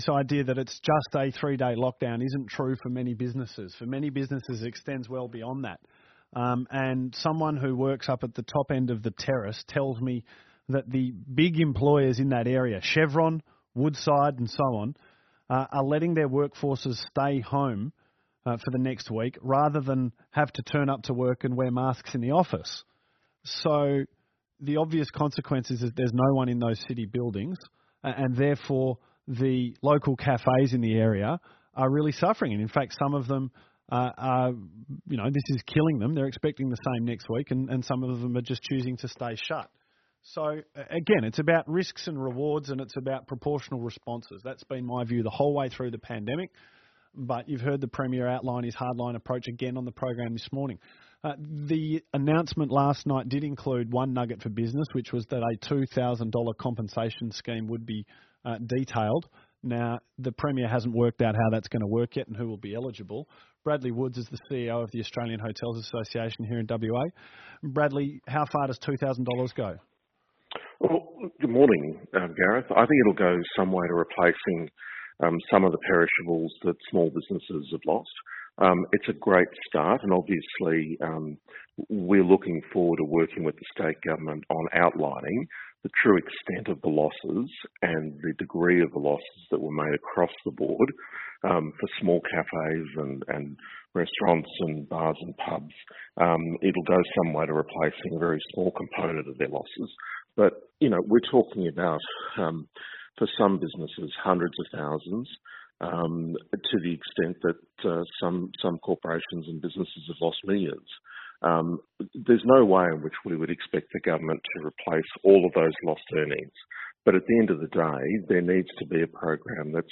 interview with 6PR – AHA reveals the impact on industry’s mental health